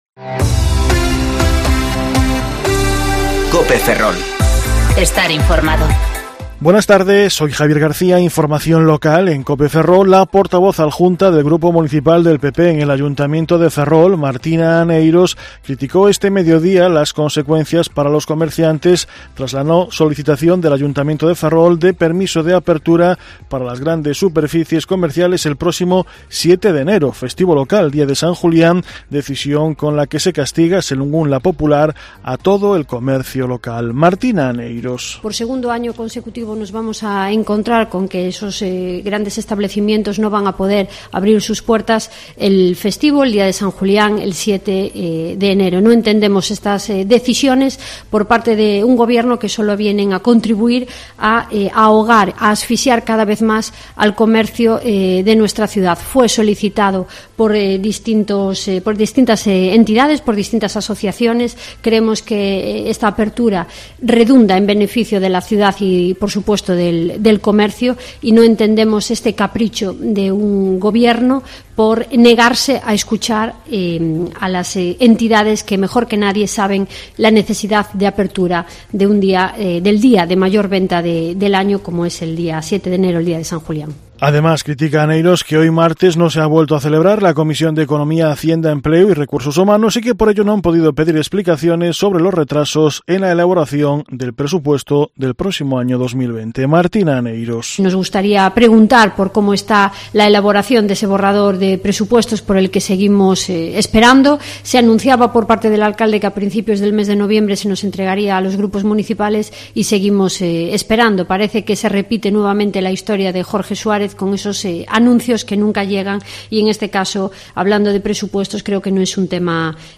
Informativo Mediodía Cope Ferrol 3/12/2019 (De 14.20 a 14.30 horas)